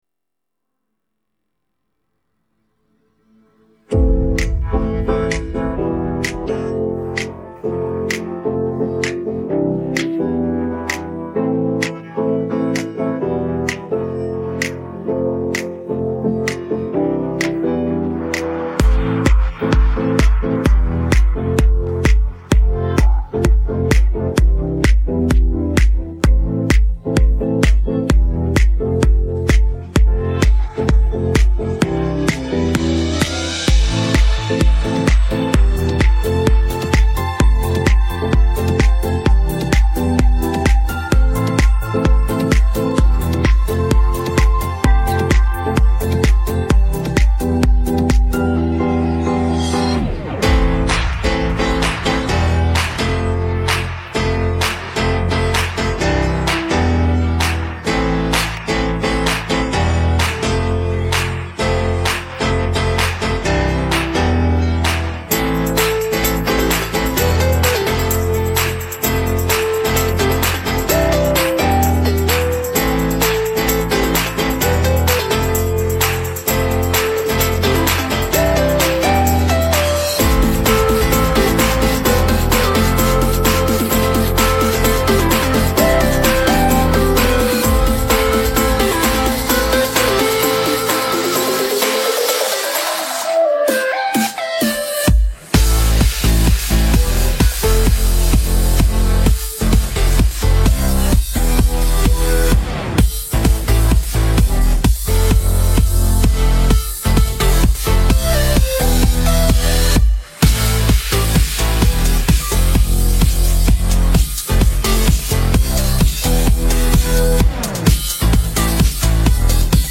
เพลงร้ย